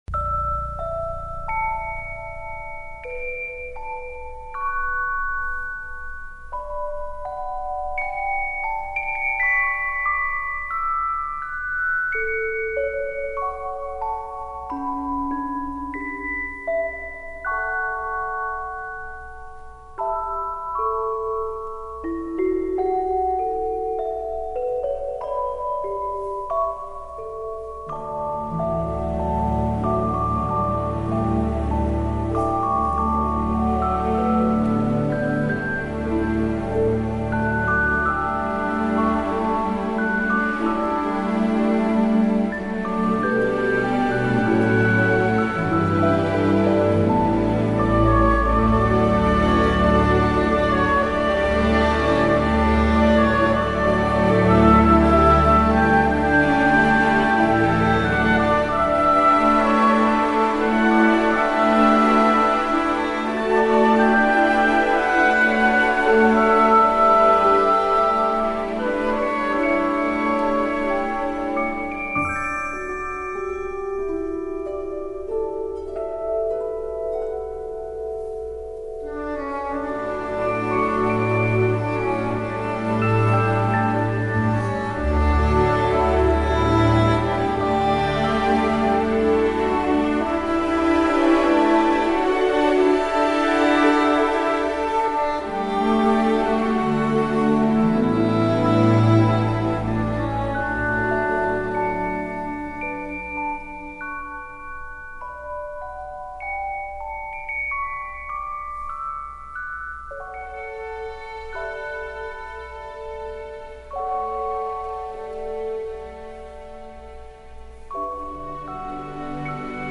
It’s soothing, soft, and ethereal.